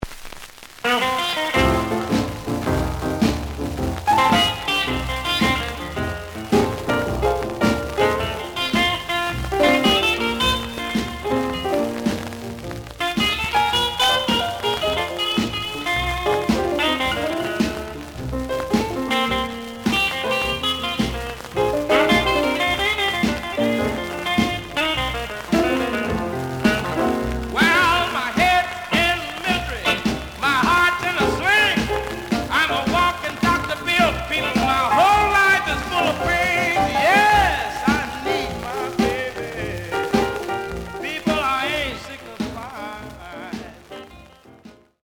The audio sample is recorded from the actual item.
●Genre: Blues
Some noise on both sides.)